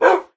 sounds / mob / wolf / bark3.ogg
bark3.ogg